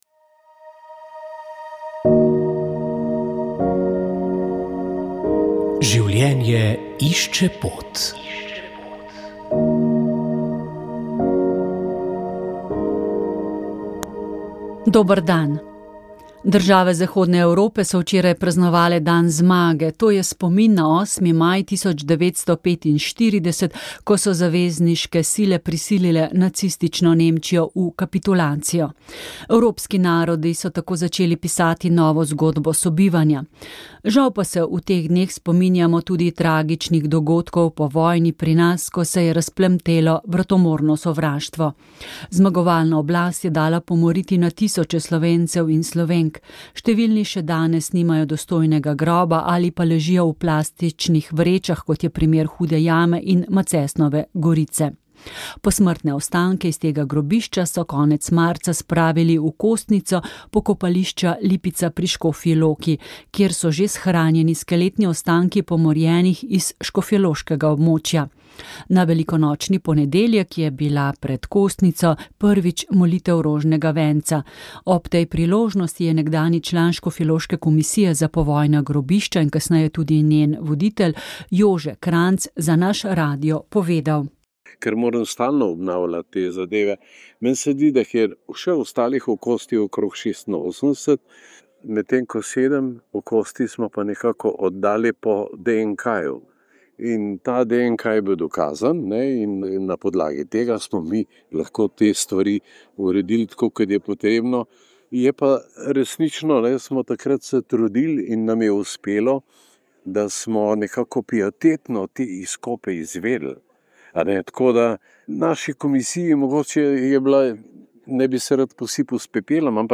Katere pomembne odločitve bi morali sprejeti, da sploh lahko govorimo prihodnosti? Na to je odgovarjal v tokratni oddaji prof. Petrič. Še prej se je ustavil pri odločitvi za samostojno Slovenijo 23. decembra 1990.